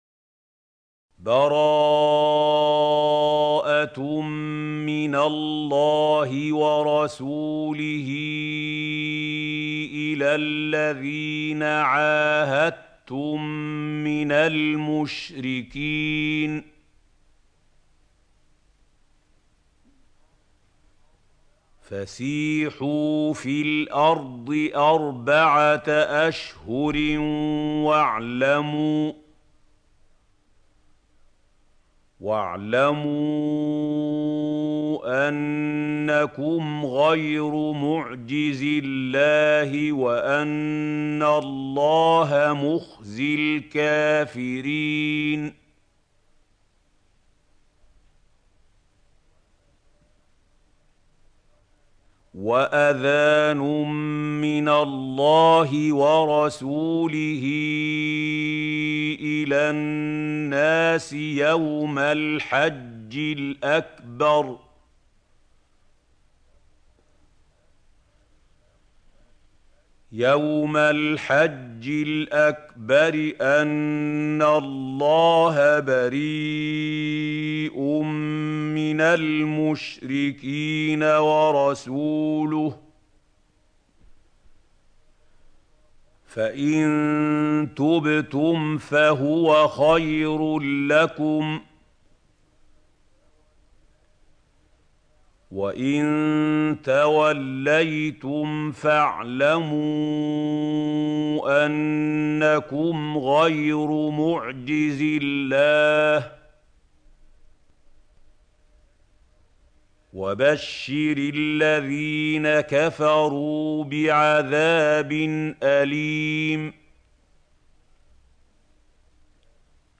سورة التوبة | القارئ محمود خليل الحصري - المصحف المعلم